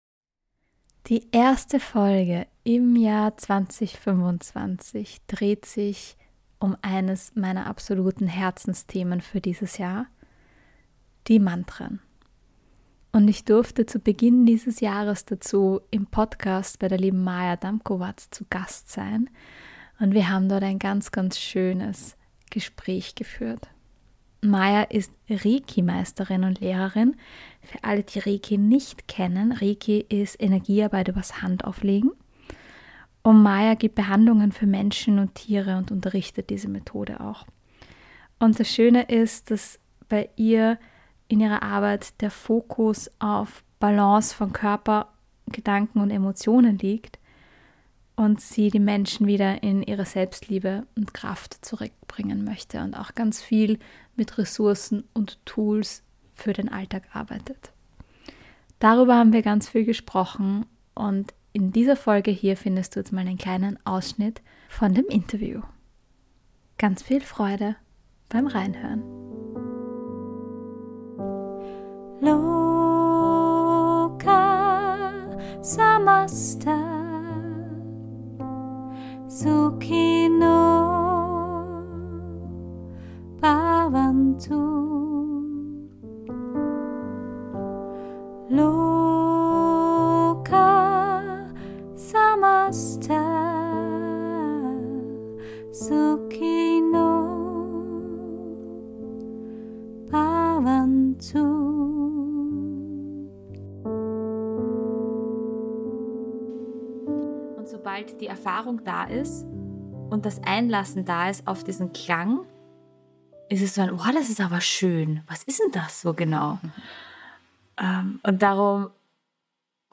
Und natürlich gibt es in dieser Folge musikalisch ein auch ein Mantra für dich Mehr